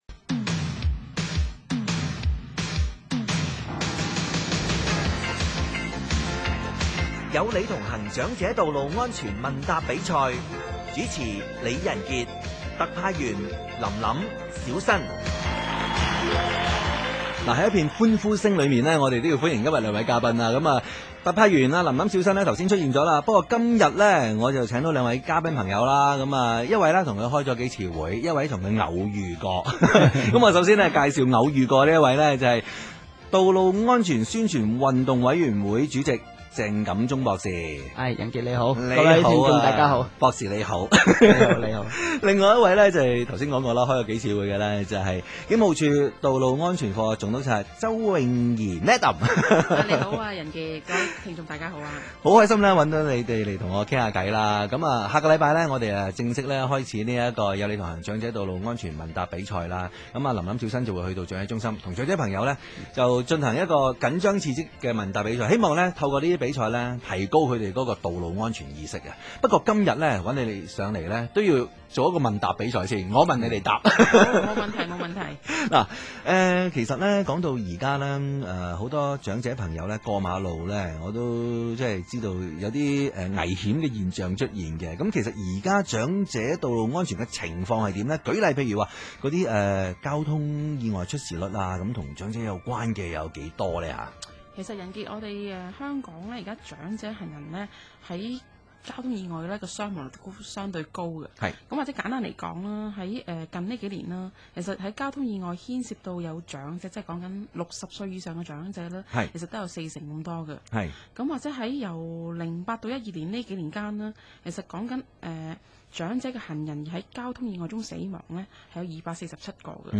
道路安全議會 - 長者道路安全問答比賽專訪